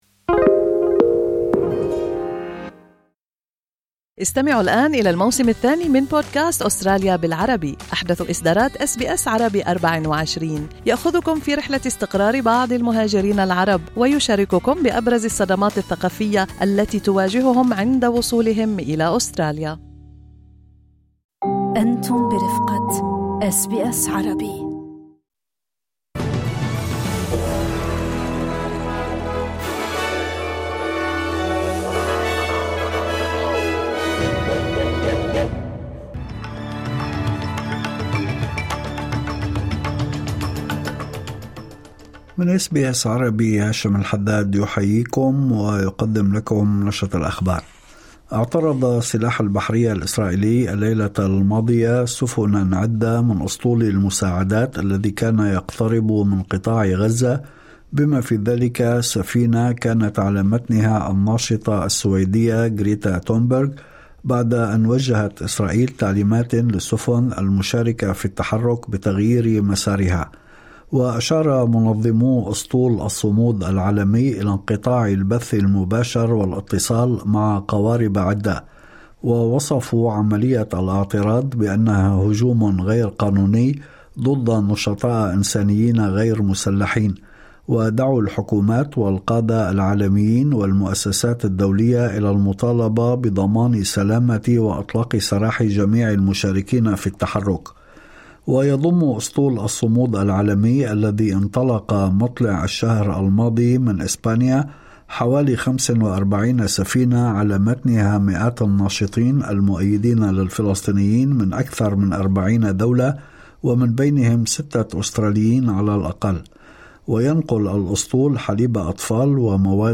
نشرة أخبار الظهيرة 02/10/2025